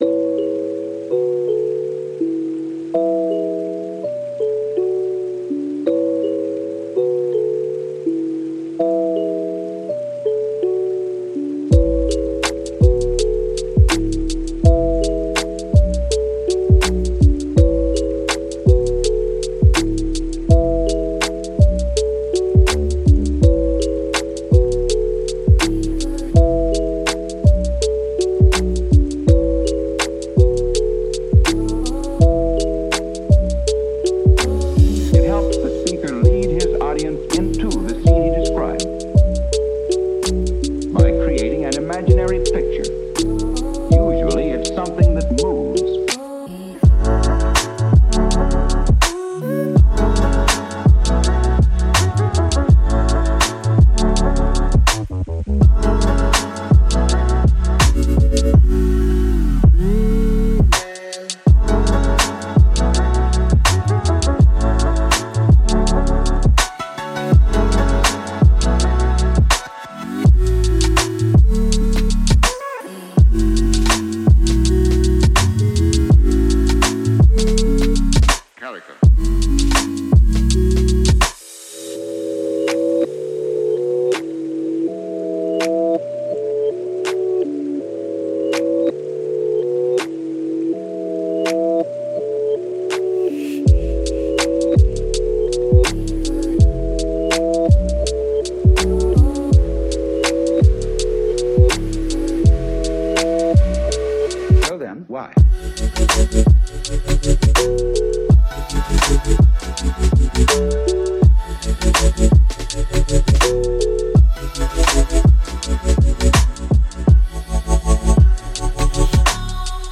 это трек в жанре ло-fi хип-хоп